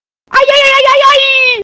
Ai ai ai ai aiiiii
aiaiaiaiii.mp3